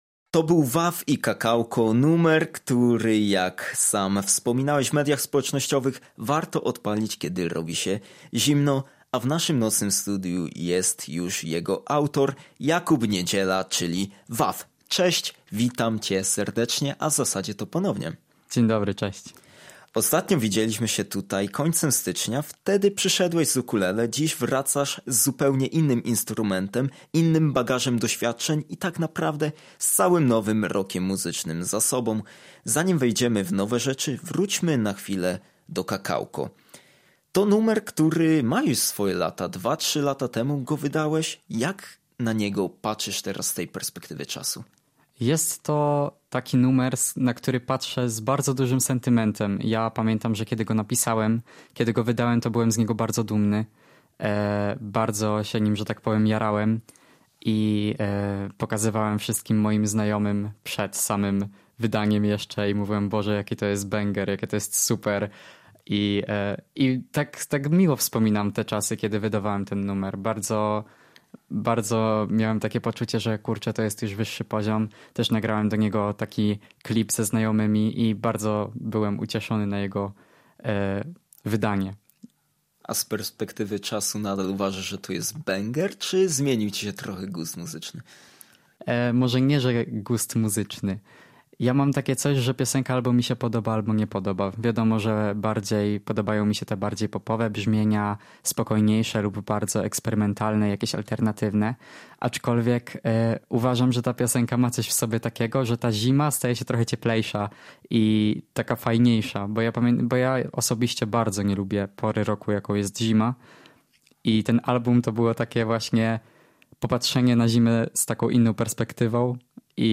Live w radiowym studiu Wyjątkowym elementem spotkania był występ na żywo .